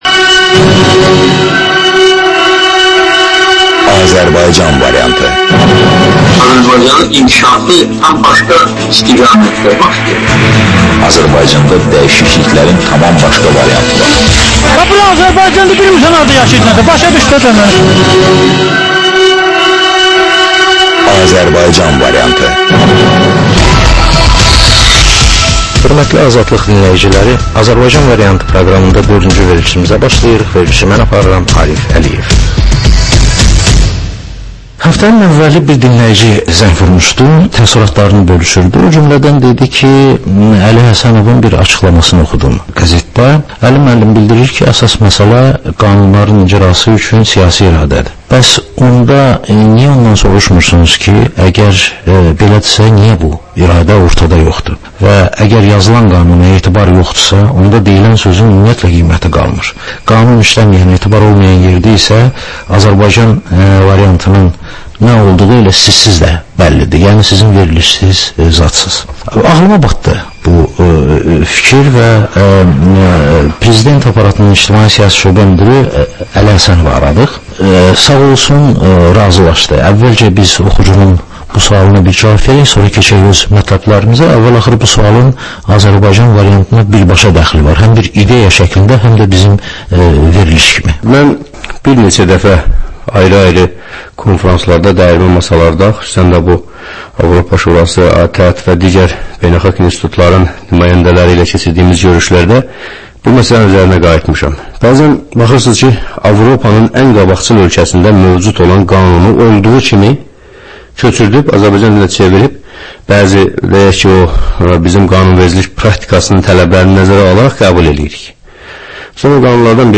Həftənin aktual məsələsi barədə dəyirmi masa müzakirəsi